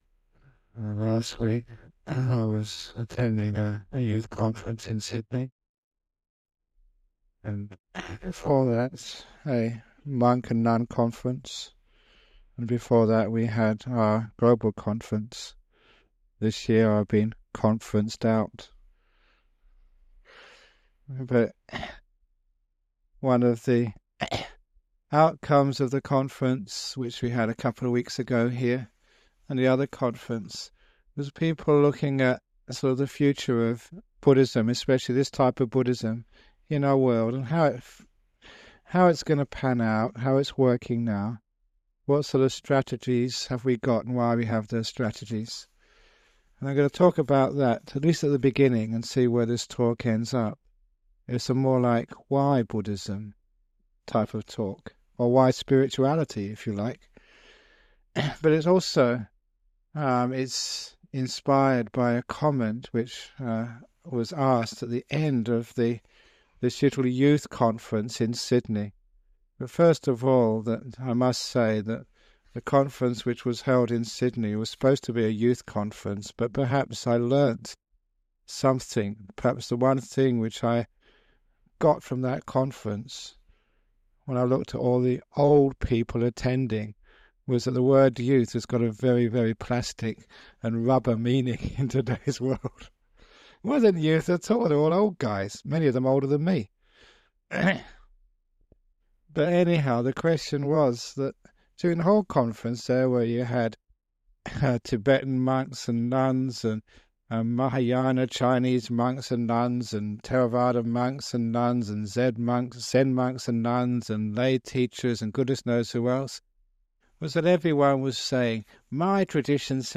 Remastered classic teachings of the greatest meditation master in the modern Western world - Ajahn Brahm!